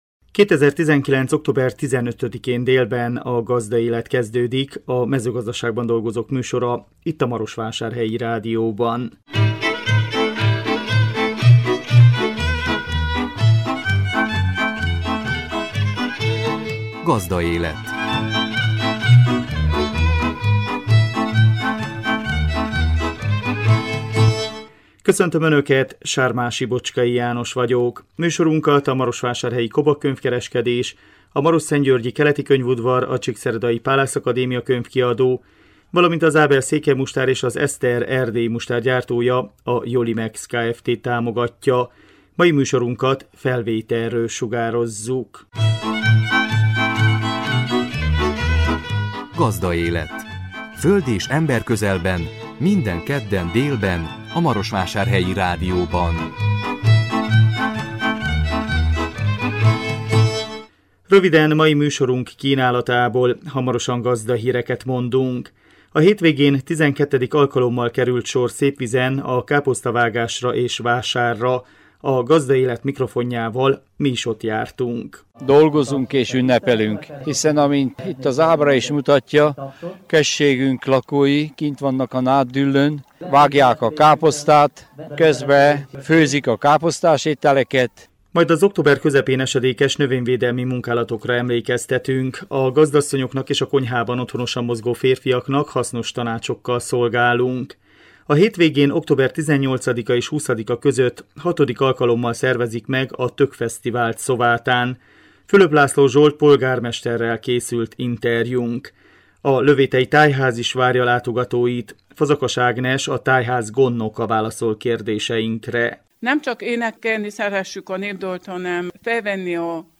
A 2019 október 15-én jelentkező műsor tartalma: Gazdahírek, A hétvégén XII. alkalommal került sor Szépvízen a káposztavágásra és vásárra. A Gazdaélet mikrofonjával mi is ott jártunk.
Fülöp László Zsolt polgármesterrel készült interjúnk.